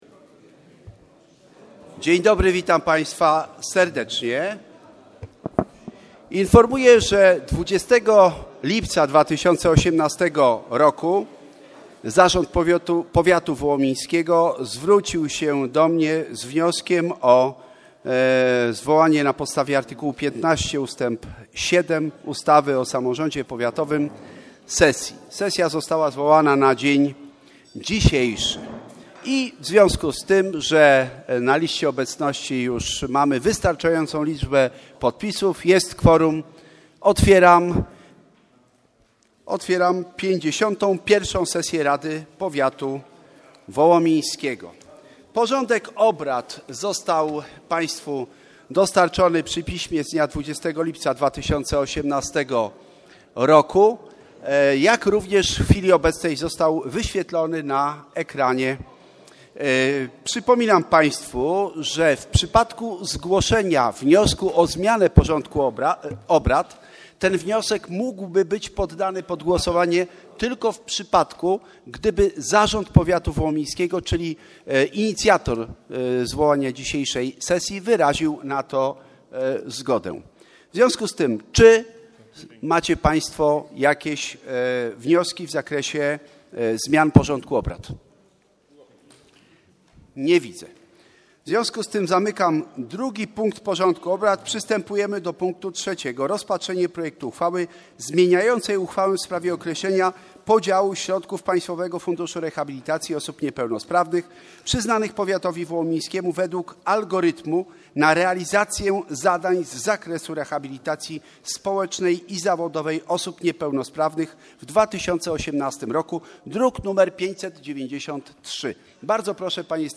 LI Sesja Rady Powiatu Wołomińskiego